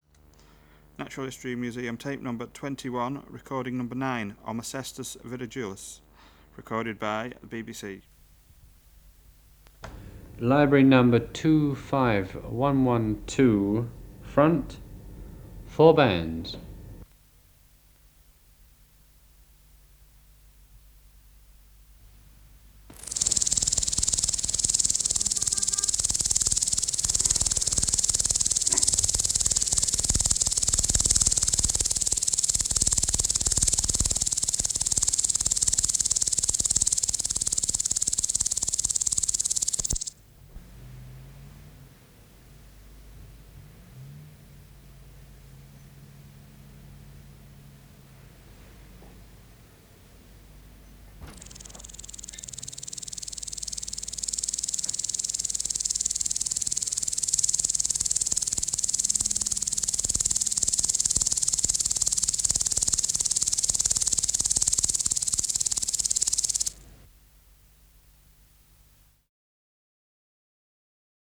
Project: Natural History Museum Sound Archive Species: Omocestus (Omocestus) viridulus